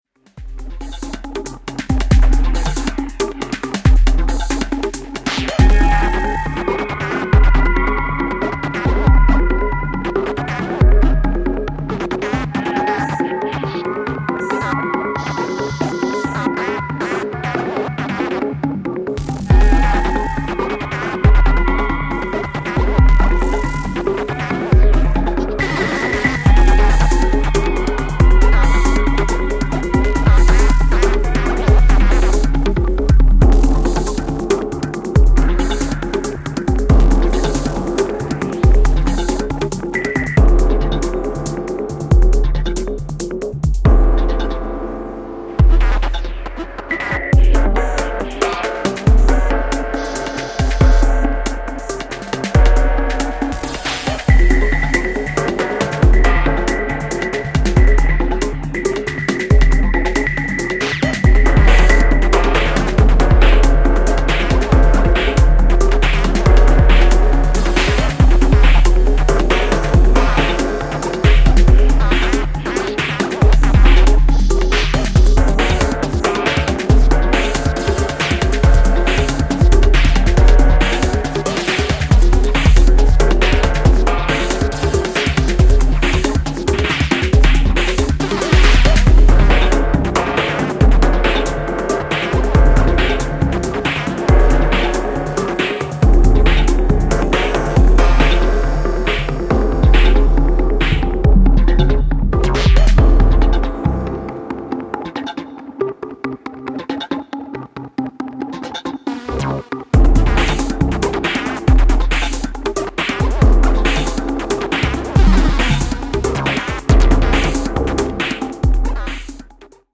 IDM influenced electro bangers
Electro Techno